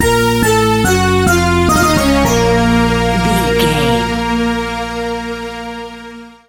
Aeolian/Minor
B♭
instrumentals
World Music
percussion